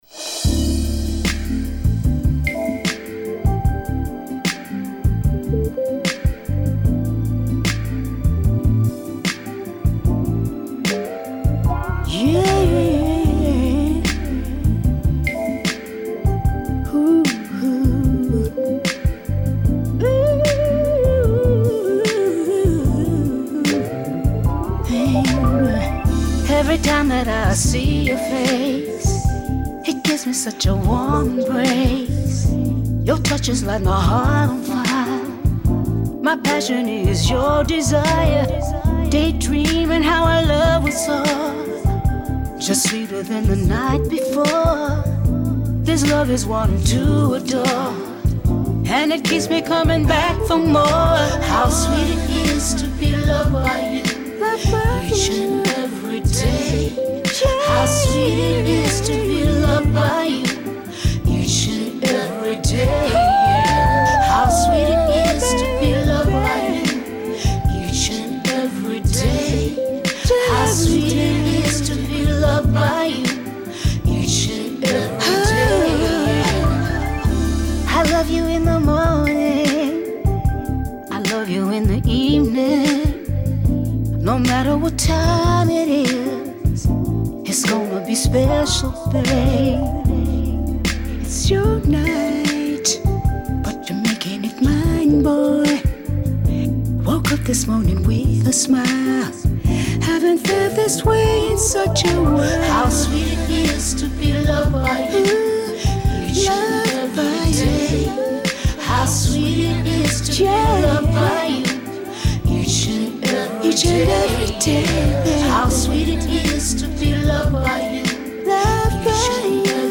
sultry new single